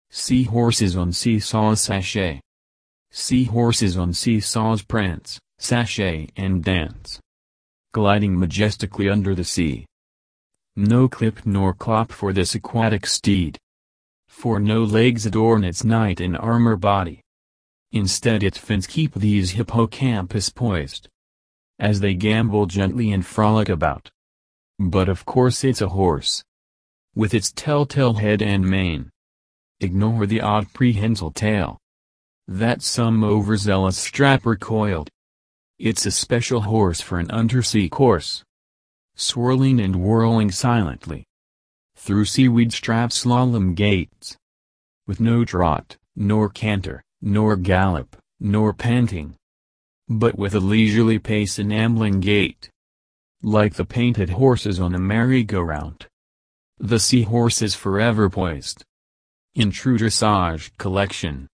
I enjoyed listening to your oral rendition.
Nice to hear it read so well also.